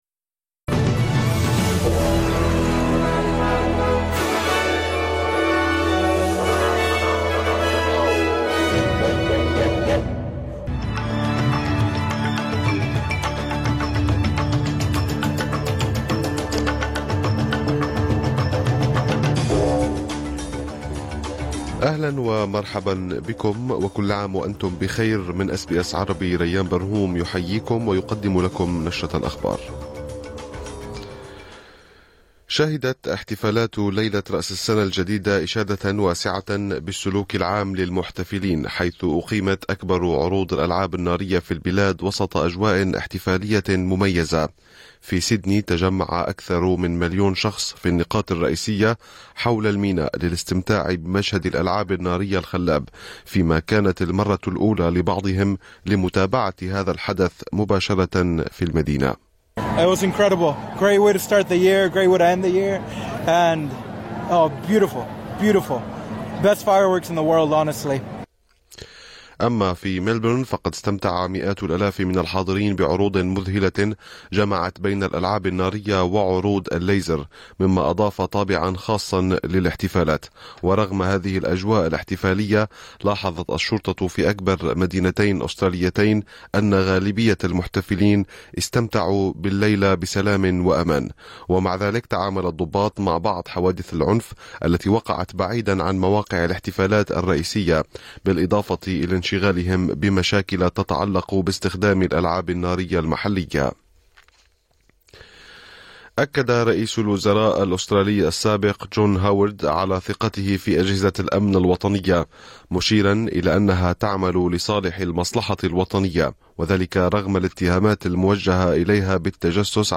نشرة أخبار الظهيرة 1/1/2024